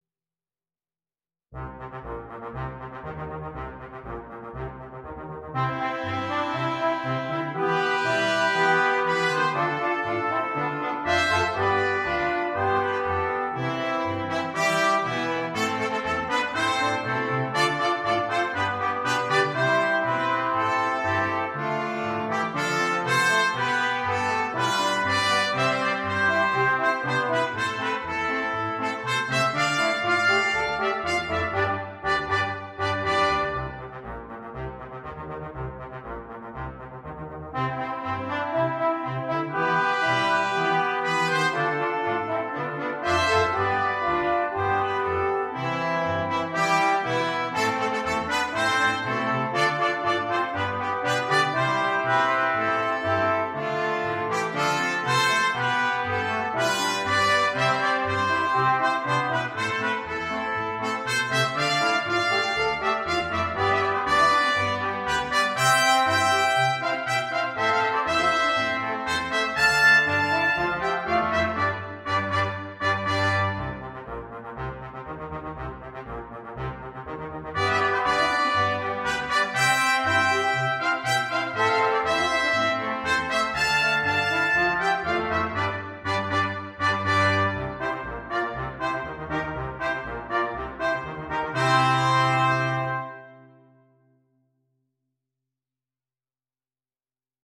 Ноты для брасс-квинтета
Trumpet in B 1, Trumpet in B 2, Horn in F, Trombone, Tuba.